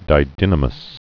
(dī-dĭnə-məs)